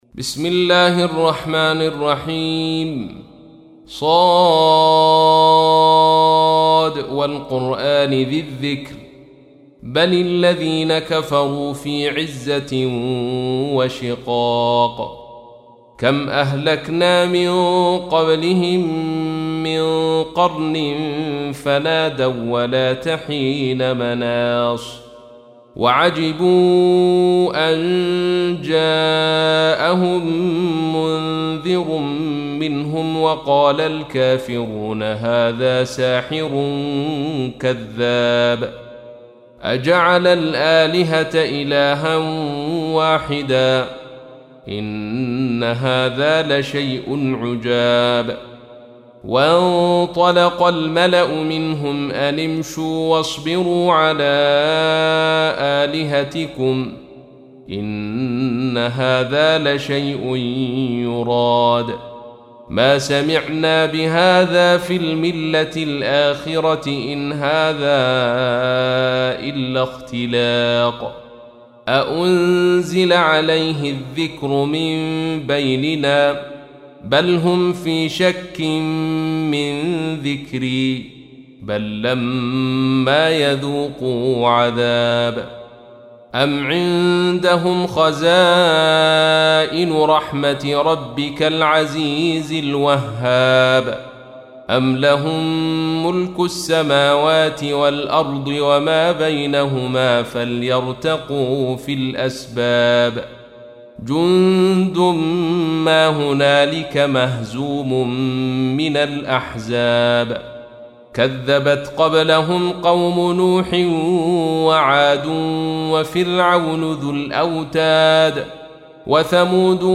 تحميل : 38. سورة ص / القارئ عبد الرشيد صوفي / القرآن الكريم / موقع يا حسين